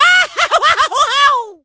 Mario's despair scream. From Mario Party 4.
Mario_(blown_away)_-_Mario_Party_4.oga